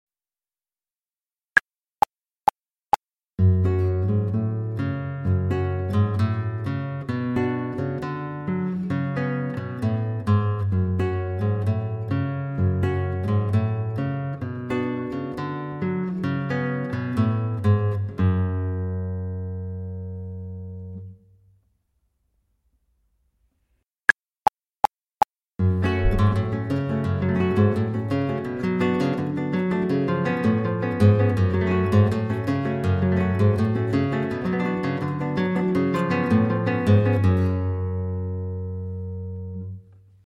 Guitar type classical guitar
• Instrumentation: Guitar